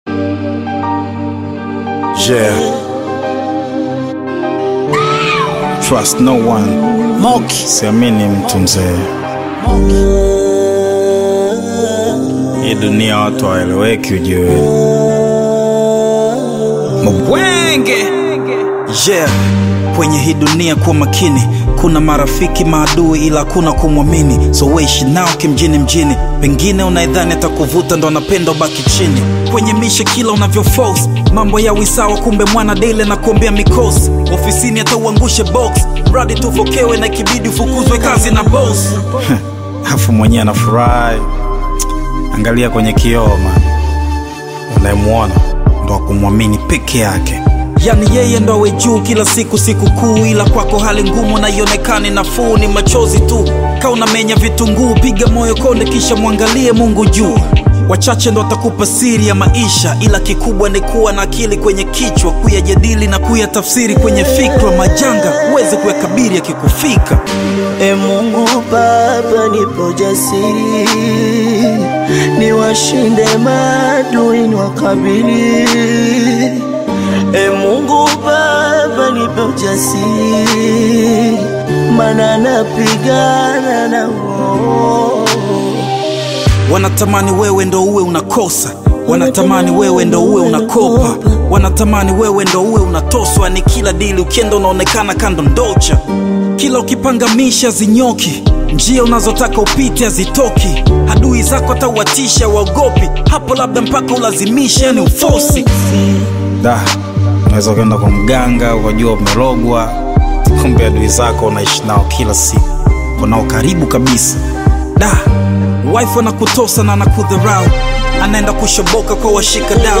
gritty Tanzanian Hip-Hop single
assertive flow
intense rap delivery